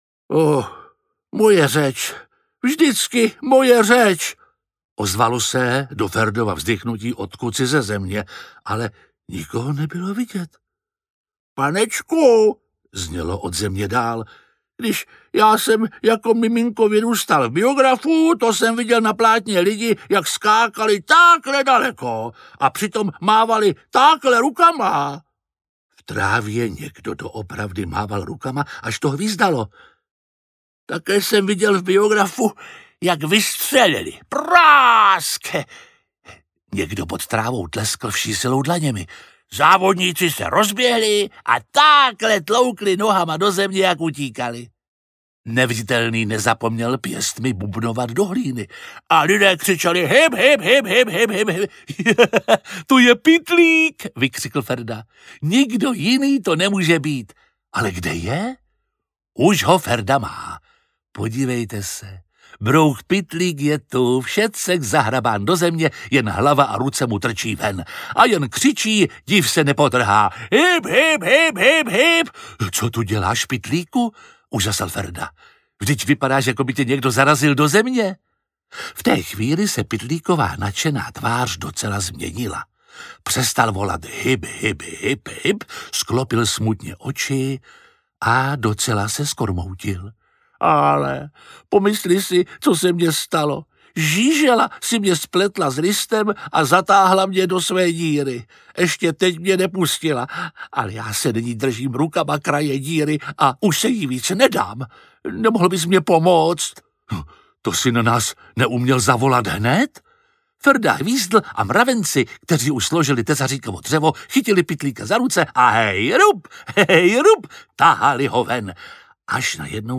Ferda cvičí mraveniště audiokniha
Ukázka z knihy
Čte Jiří Lábus.
Vyrobilo studio Soundguru.